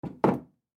Легкое постукивание задними лапами